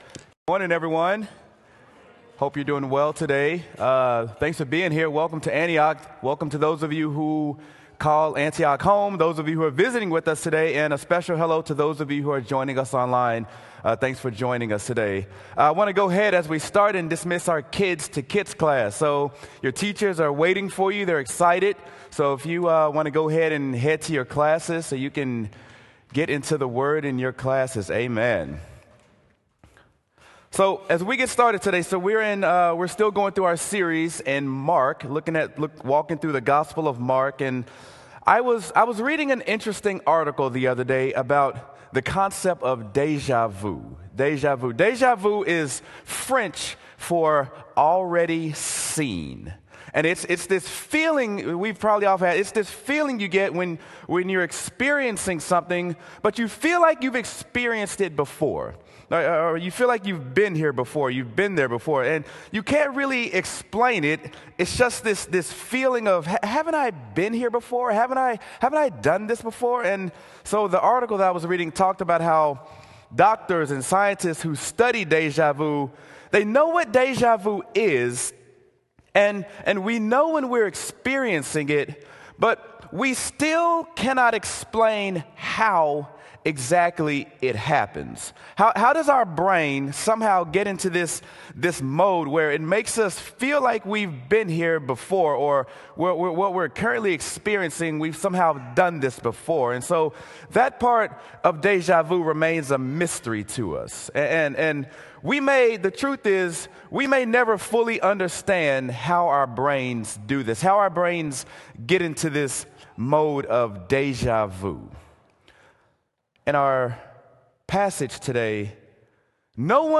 Sermon: Mark: I've Seen This Before | Antioch Community Church - Minneapolis
sermon-mark-ive-seen-this-before.m4a